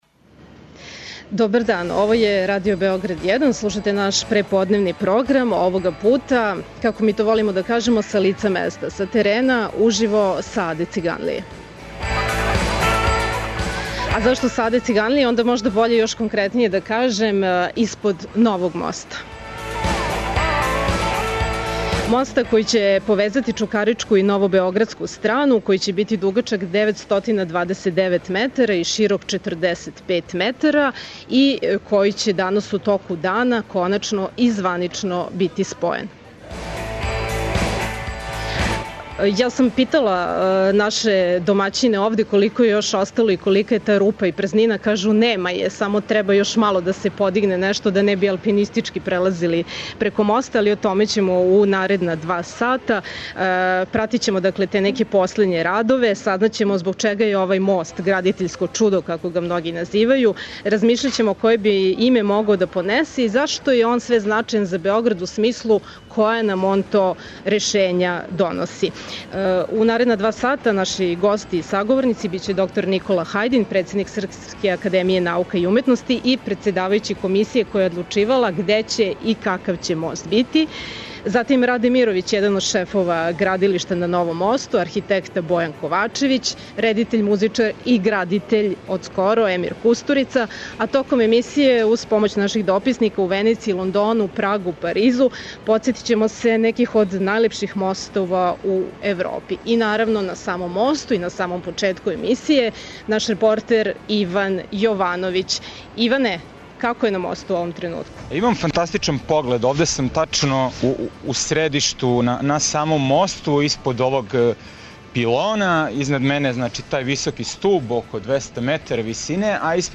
Две стране моста преко Саве код Аде Циганлије, чукаричка и новобеоградска, биће данас спојене, тако што ће хирауличне машине подићи део моста који се налази на Новом Београду и 'одгурати га' до главног распона који почиње на Чукарици, и виси над Савом. Магазин Београда 1 је на лицу места!